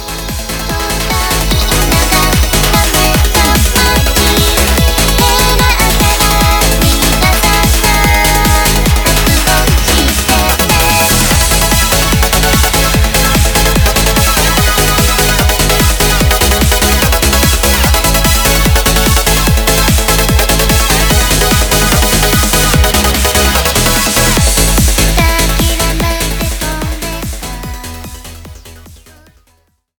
EUROBEAT